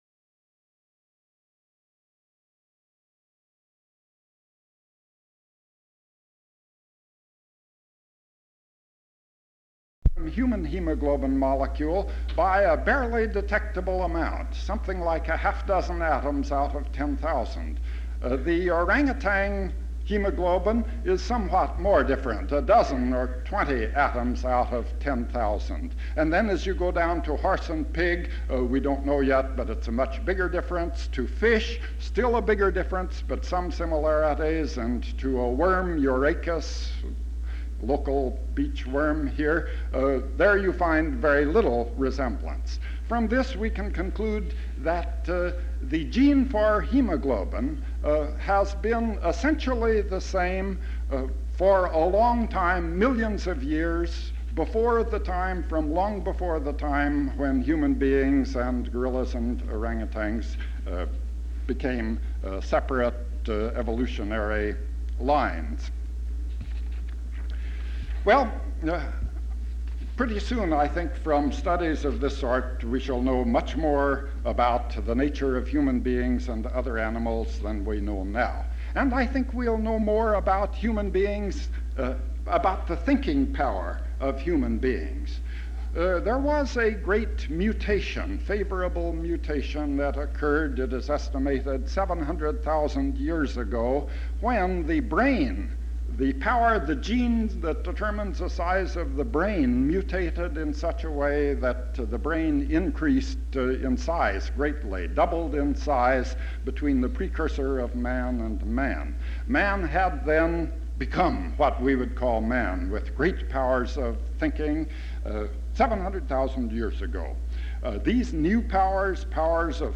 1960 California Library Association recording of Linus Pauling speech
Form of original Open reel audiotape